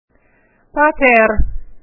Words and Phrases will have separate files so that you can listen to the correct pronunciation of the words.